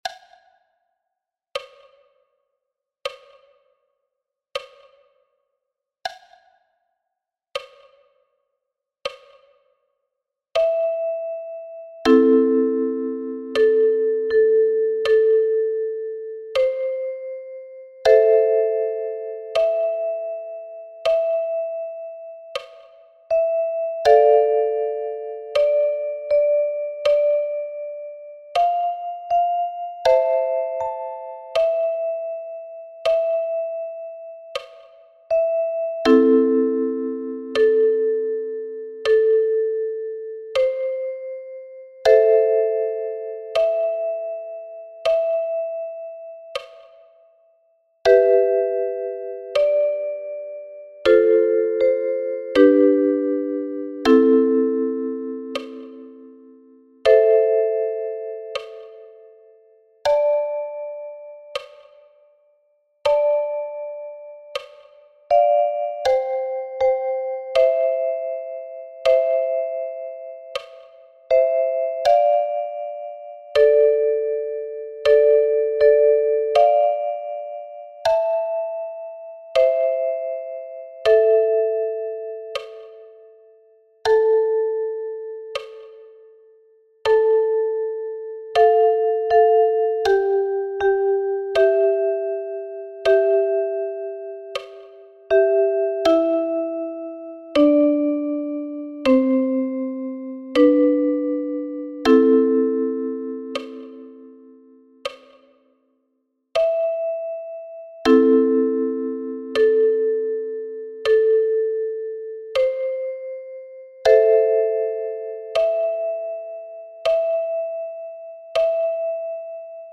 Liederbuch (in deutsch und english) mit 30 Bearbeitungen traditioneller Seemannslieder – für die Ukulele.
Alle Lieder sind mit Tabulaturen für das Fingerpicking notiert, die Arrangements sind leicht bis mittelschwer gesetzt – für Anfänger und leicht fortgeschrittene Fans der Ukulele.